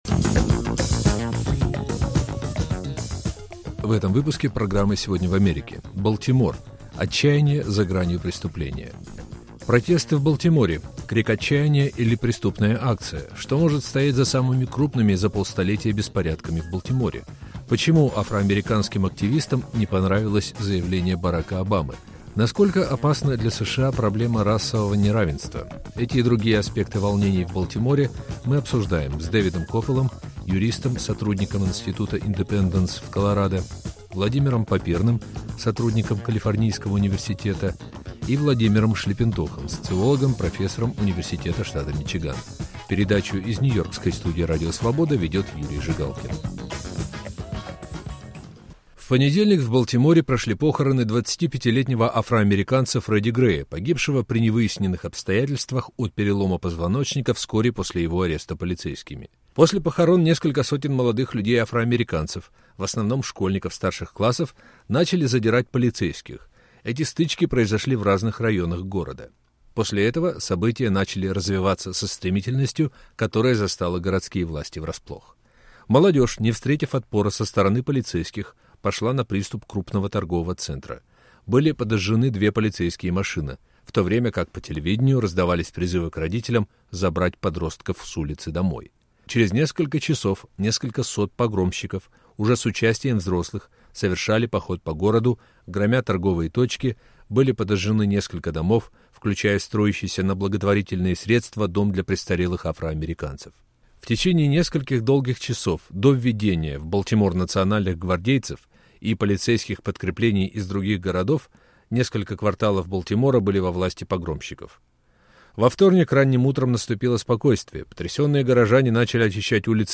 Насколько опасна для США проблема расового неравенства? Обсуждают американские эксперты.